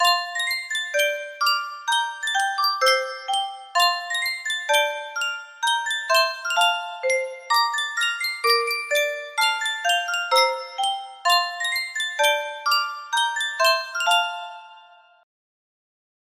Sankyo Music Box - In the Bleak Midwinter NHY music box melody
Full range 60